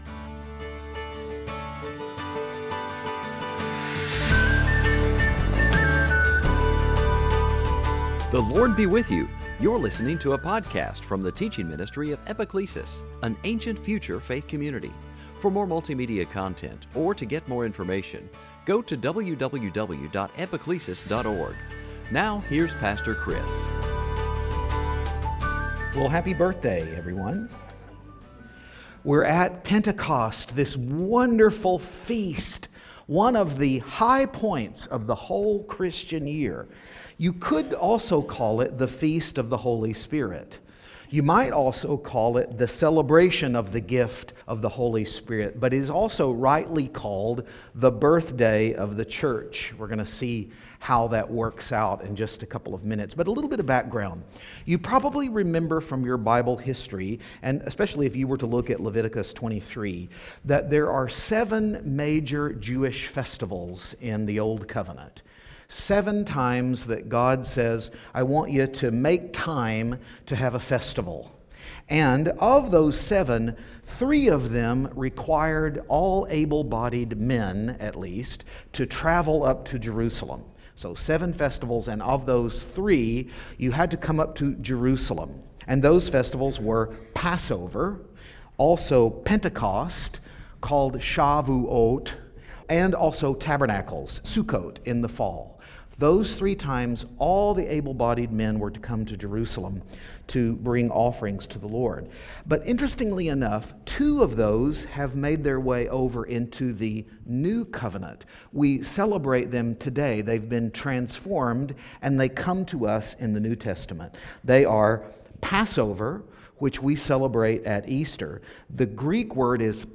2018 Sunday Teaching feasts harvest Holy Spirit law Mt. Zion Passover Pentecost Sinai Pentecost Sunday